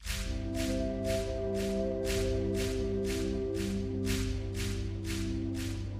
سینه زنی و ذکر حسین | دانلود انواع ریتم های سینه زنی
سینه زنی و ذکر حسین | دانلود صدای سینه زنی سه ضربی | دانلود صدای سینه زنی شور طوفانی | دانلود ذکر حسین حسین بیس دار | دانلود صدای هیئت
demo-sinezani-moharram.mp3